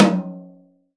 • Acoustic Tom Drum Single Hit F Key 06.wav
Royality free tom drum one shot tuned to the F note. Loudest frequency: 956Hz
acoustic-tom-drum-single-hit-f-key-06-1nw.wav